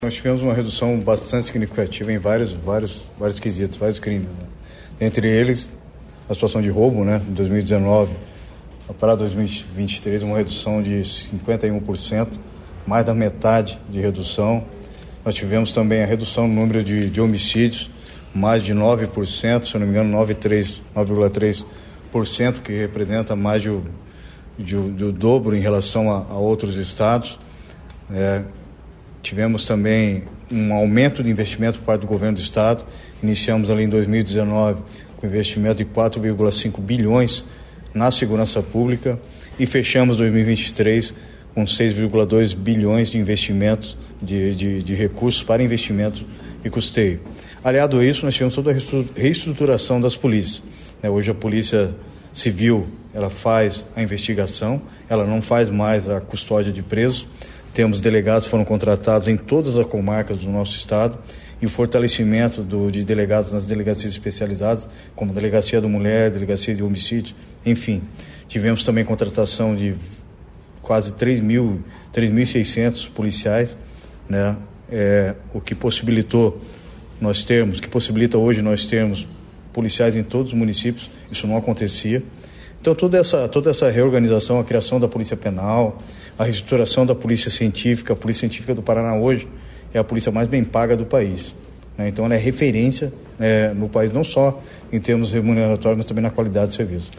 Sonora do secretário da Segurança Pública, Hudson Teixeira, sobre o balanço do setor no Paraná em 2023